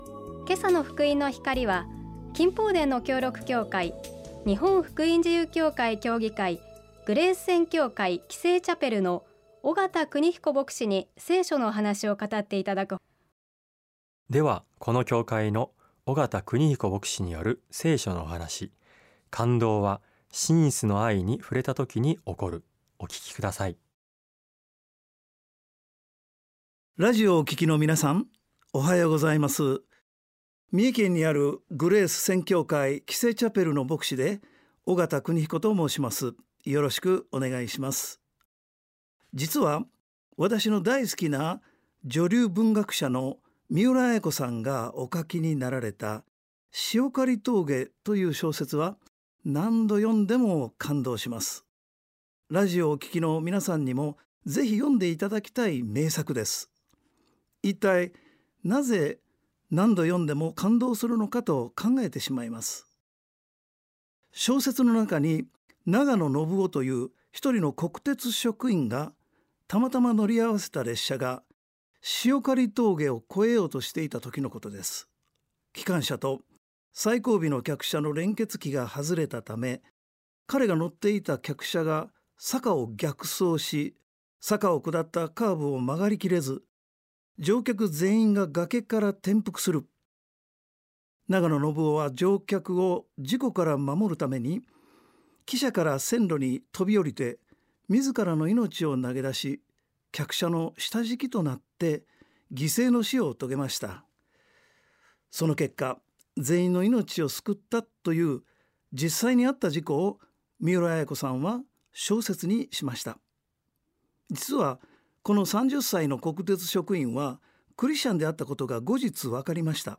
聖書のお話「感動は、真実の愛に触れた時に起こる」